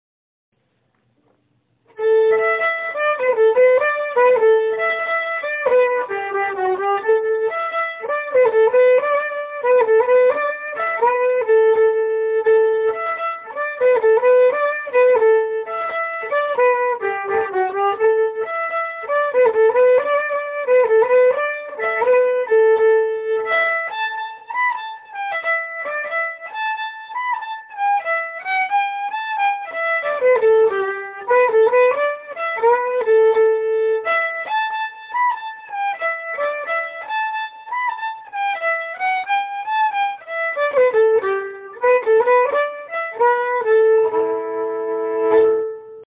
Key: Am
Form: Slide*
Played slowly for learning
N: hexatonic
M:12/8
Genre/Style: Irish slide (12/8)